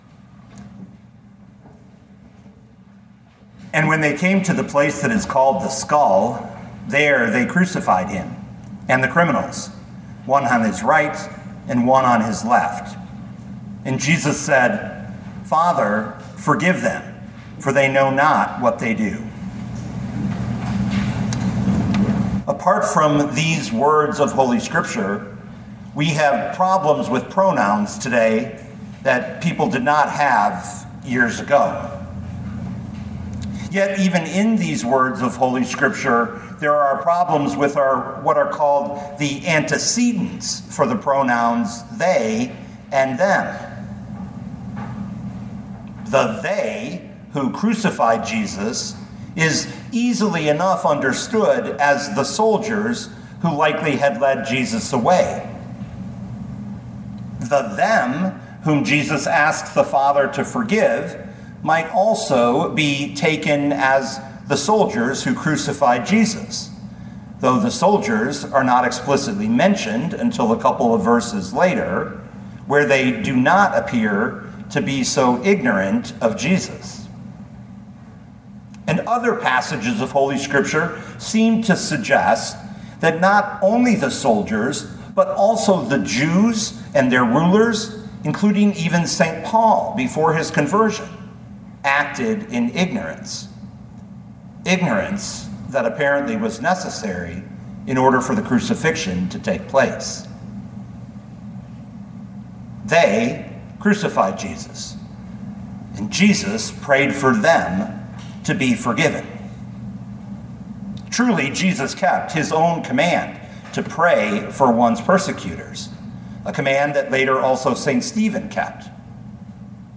Luke 23:46 Listen to the sermon with the player below, or, download the audio.